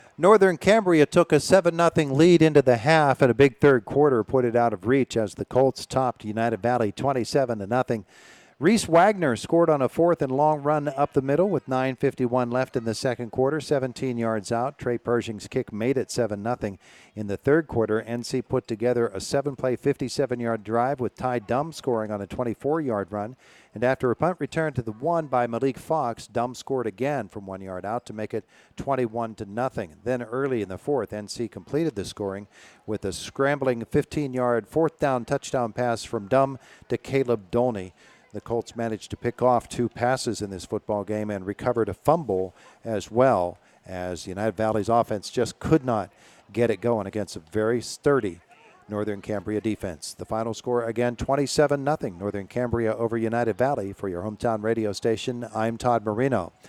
nc-v-uv-recap.mp3